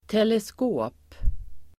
Ladda ner uttalet
Uttal: [telesk'å:p]